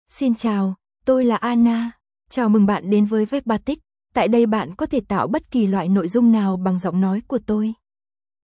AnnaFemale Vietnamese AI voice
Anna is a female AI voice for Vietnamese (Vietnam).
Voice sample
Listen to Anna's female Vietnamese voice.
Anna delivers clear pronunciation with authentic Vietnam Vietnamese intonation, making your content sound professionally produced.